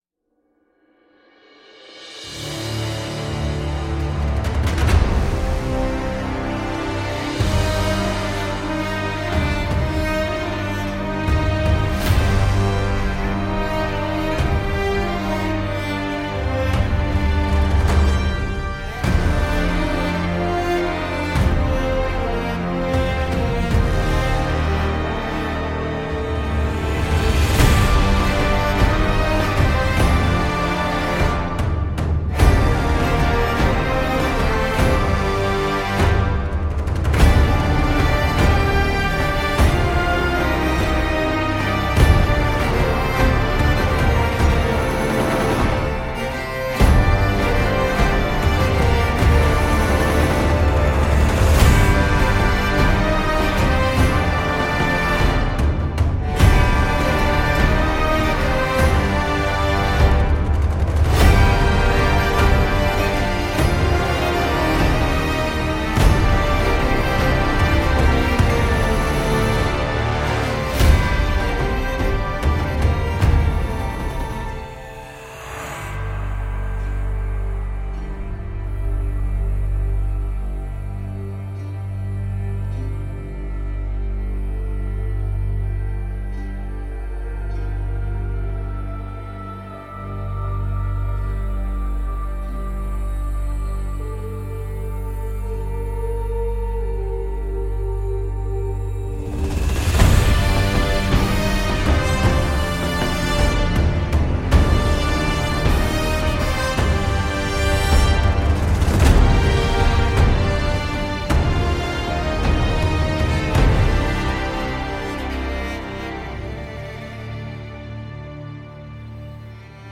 chants moyenâgeux